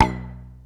3. 03. Percussive FX 02 ZG